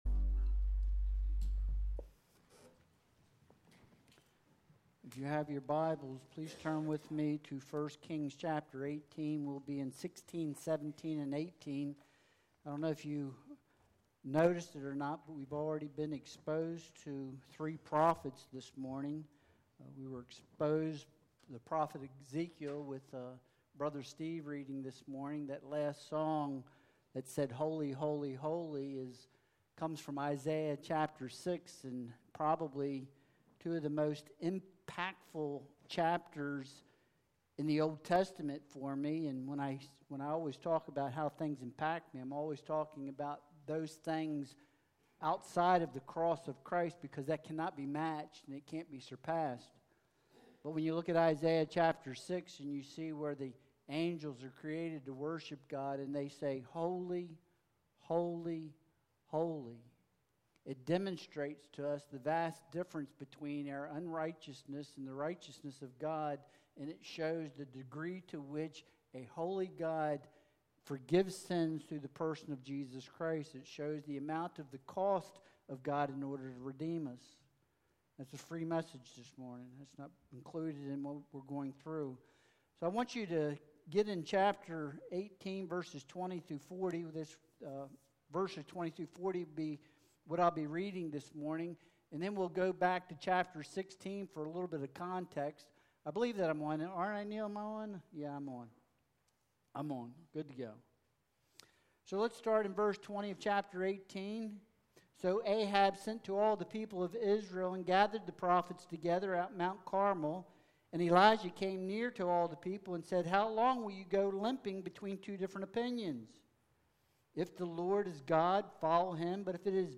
1 Kings 18:20-40 Service Type: Sunday Worship Service Soggy Sacrifices Suffice Download Files Bulletin « Elijah